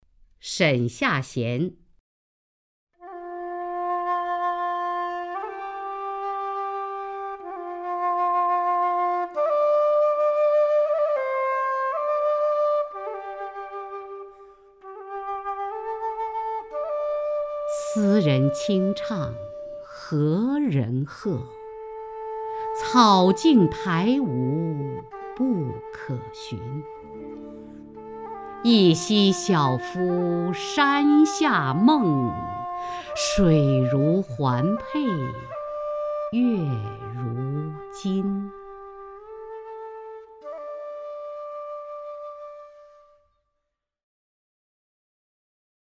雅坤朗诵：《沈下贤》(（唐）杜牧) (右击另存下载) 斯人清唱何人和， 草径苔芜不可寻。